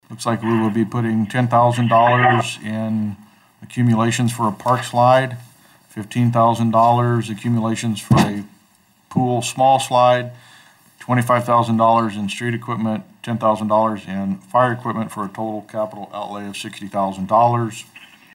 Capital outlay transfers were approved.  Mayor Cox had the details on where the money is headed.